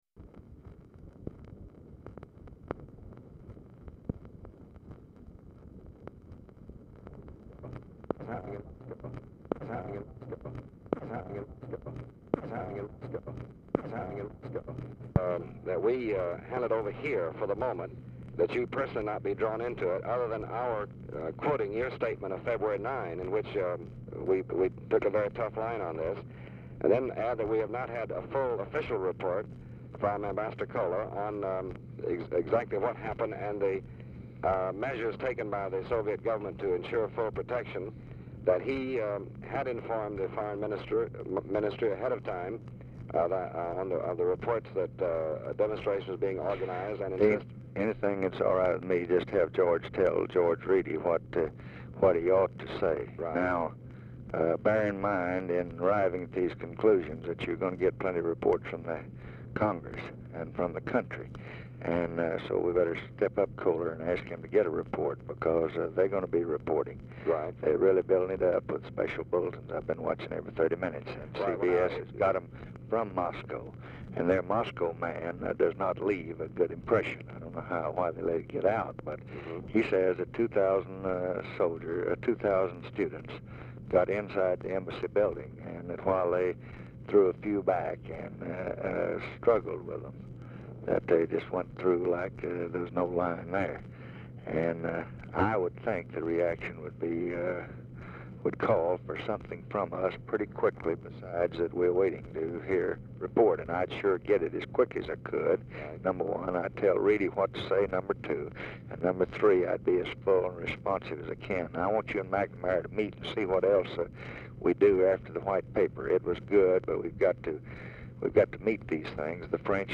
Telephone conversation # 7016, sound recording, LBJ and DEAN RUSK, 3/4/1965, 10:41AM
SKIPPING AT BEGINNING OF RECORDING; RECORDING STARTS AFTER CONVERSATION HAS BEGUN
Format Dictation belt
Location Of Speaker 1 Mansion, White House, Washington, DC